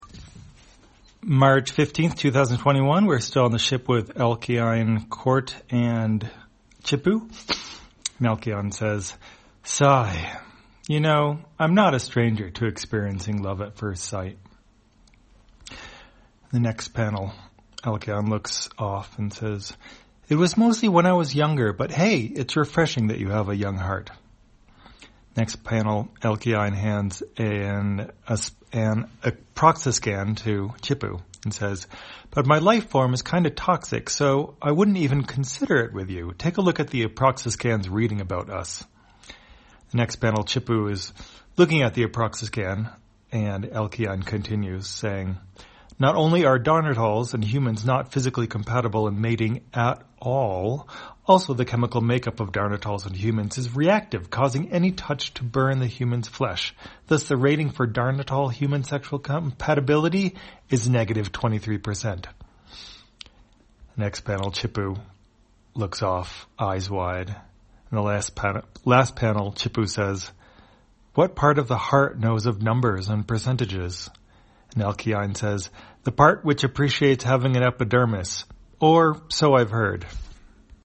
Spacetrawler, audio version For the blind or visually impaired, March 15, 2021.